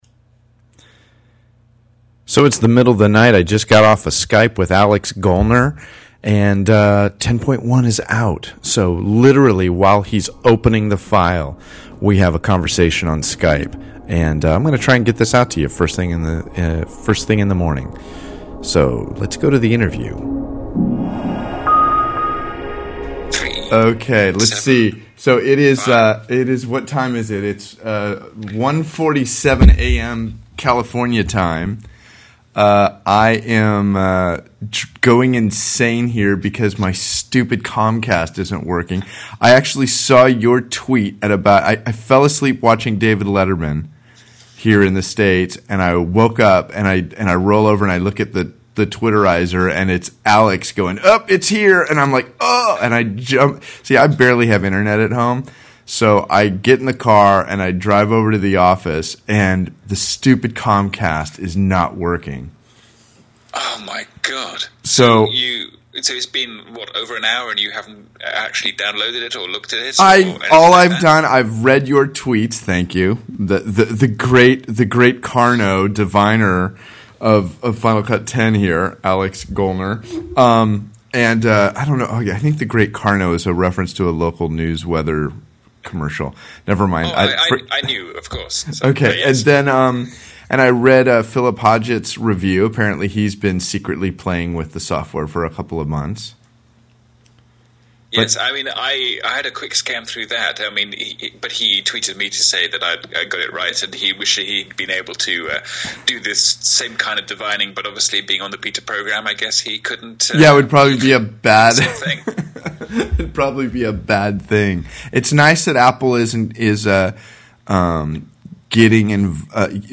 This is a very rough recording.